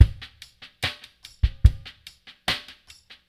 BOL LOFI 1-R.wav